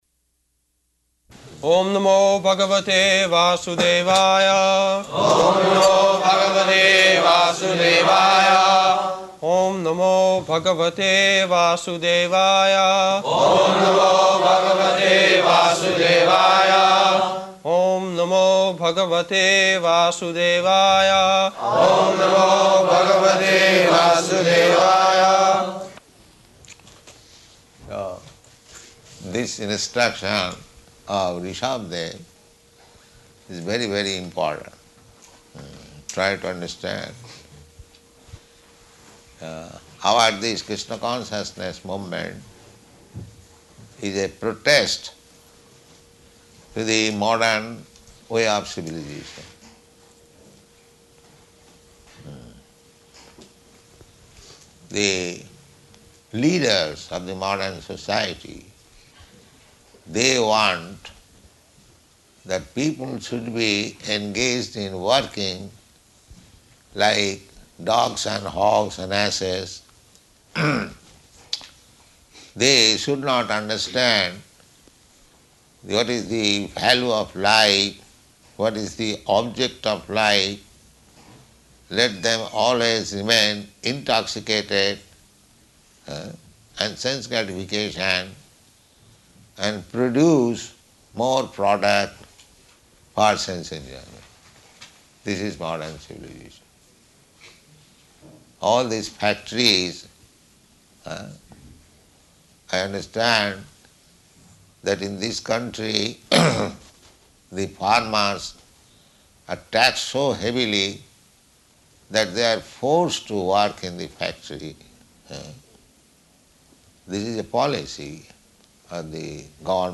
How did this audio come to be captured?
September 8th 1973 Location: Stockholm Audio file